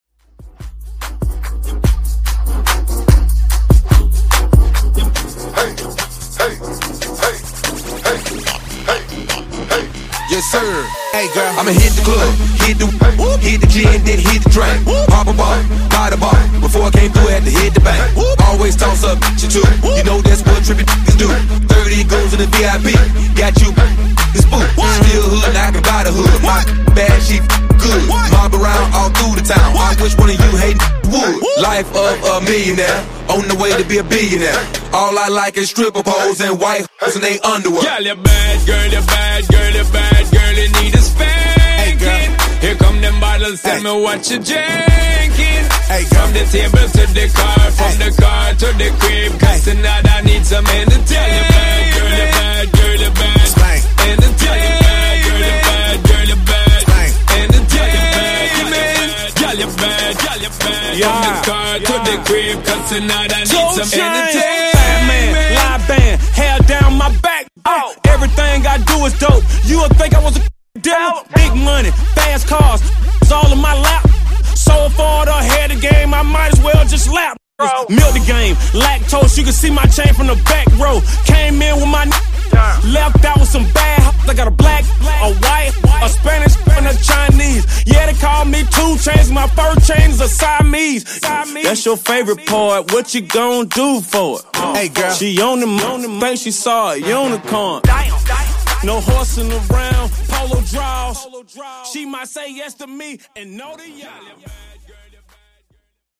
BPM: 78 Time